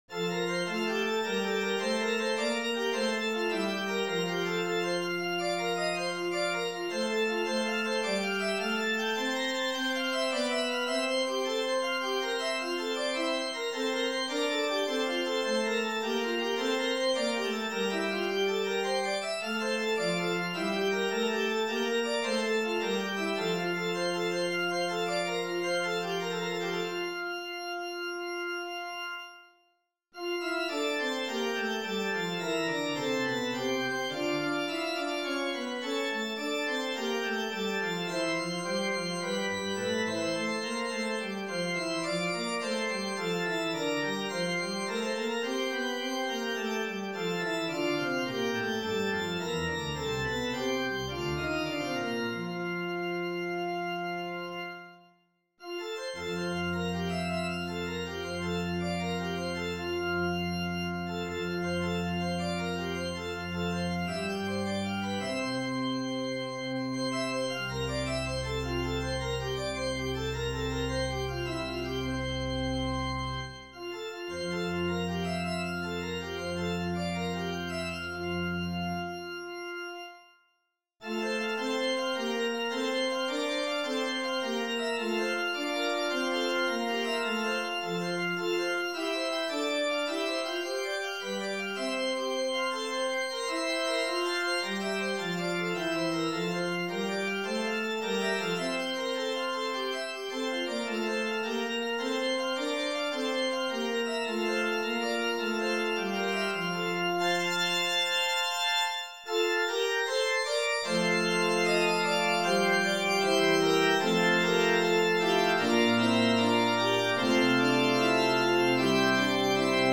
for organ